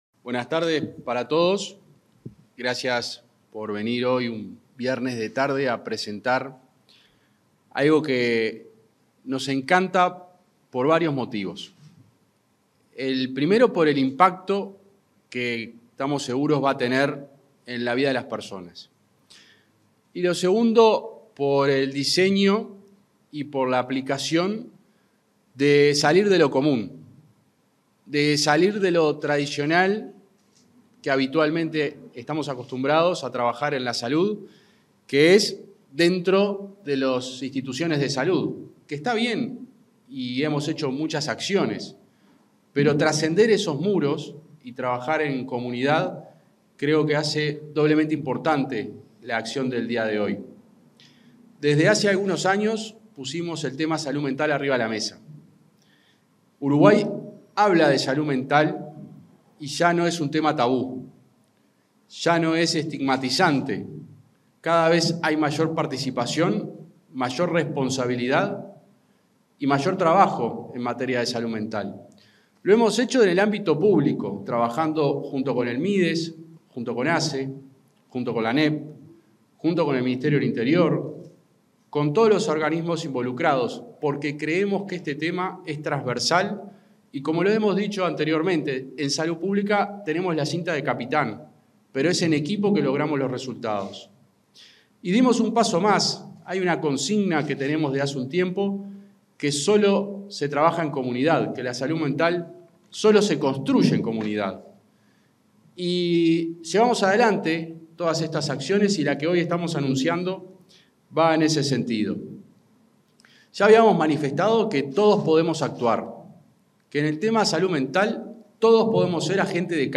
Palabras del subsecretario de Salud Pública, José Luis Satdjian
Palabras del subsecretario de Salud Pública, José Luis Satdjian 11/10/2024 Compartir Facebook X Copiar enlace WhatsApp LinkedIn En el marco del lanzamiento de la estrategia de capacitación de referentes comunitarios sobre salud mental, este 11 de octubre, se expresó el subsecretario de Salud Pública, José Luis Satdjian.